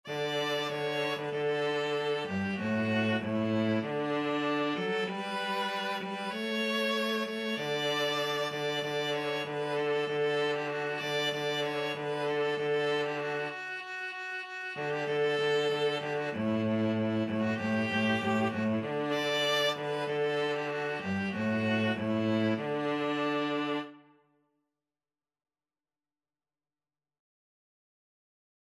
Christmas carol
2/4 (View more 2/4 Music)
Viola-Cello Duet  (View more Easy Viola-Cello Duet Music)